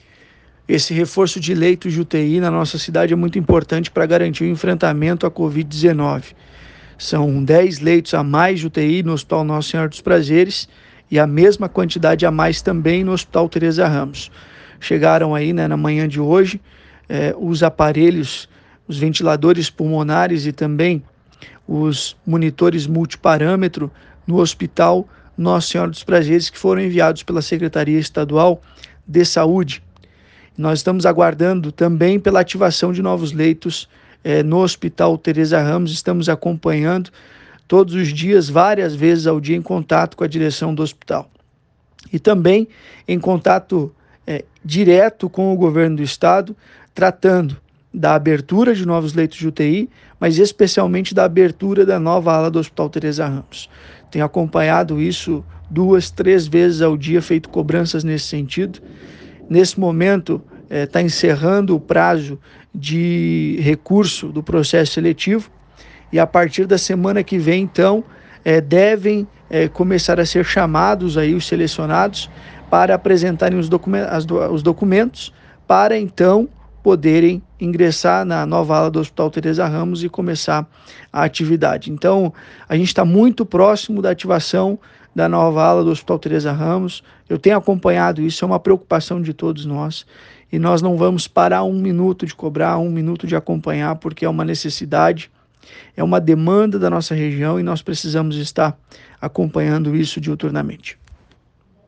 Ouça também abaixo, o que o vereador fala sobre o seu envolvimento na remessa de equipamentos e sobre a nova ala do HTR: